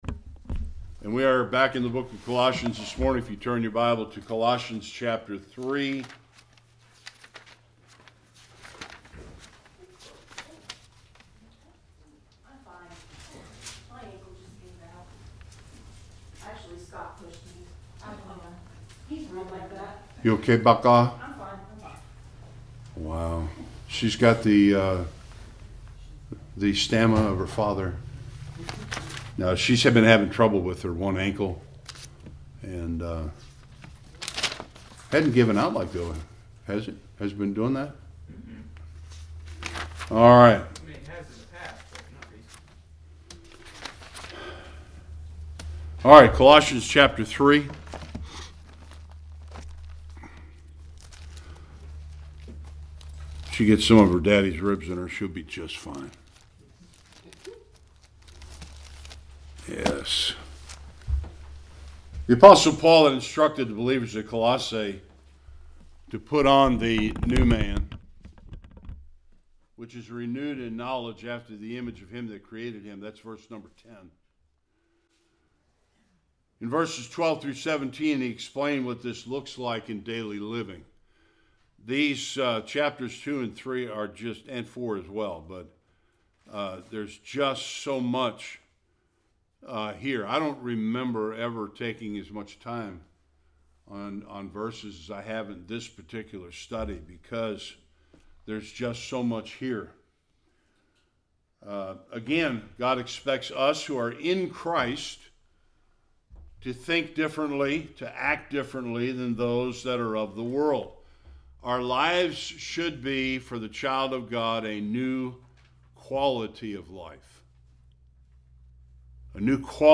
12-14 Service Type: Sunday Worship If we are in Christ